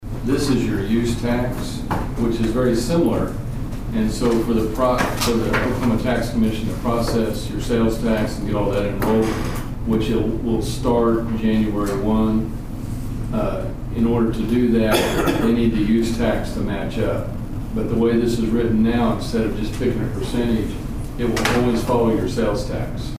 The Nowata City Commissioners met for their regularly scheduled meeting in the month of October at the Nowata Fire Department on Monday evening.